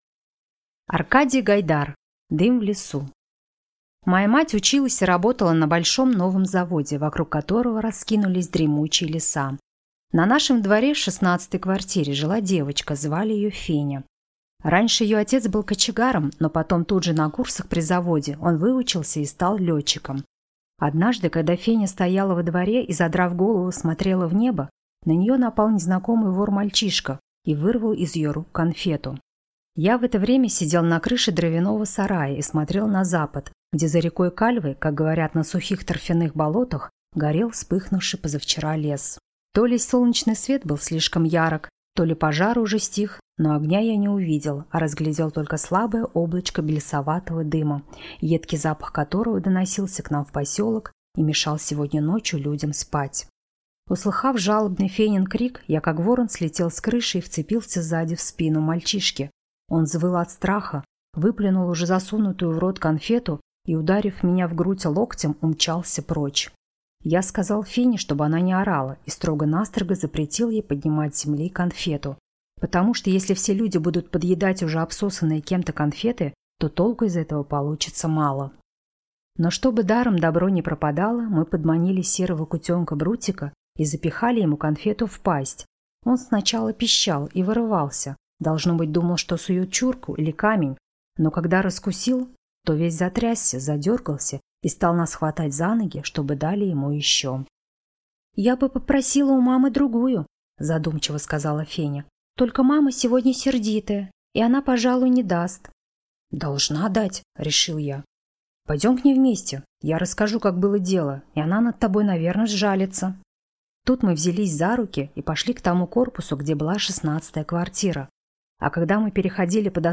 Аудиокнига Дым в лесу | Библиотека аудиокниг